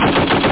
1 channel
CANNON.mp3